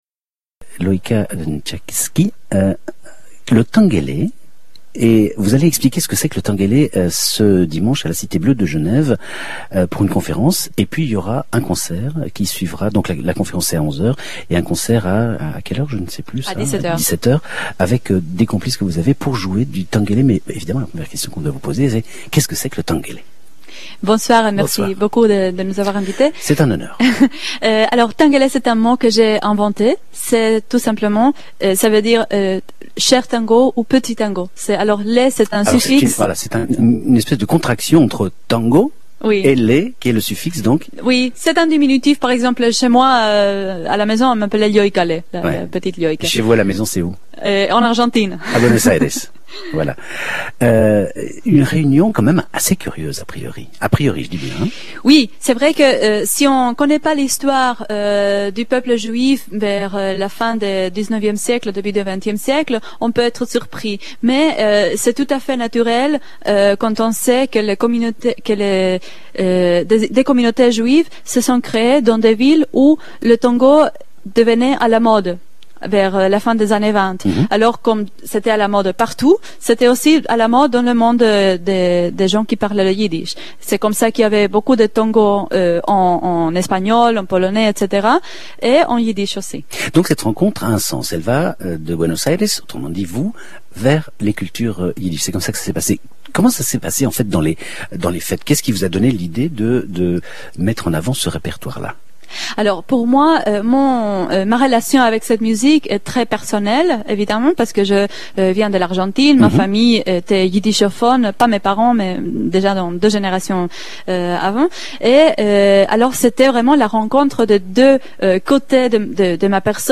On radio Interview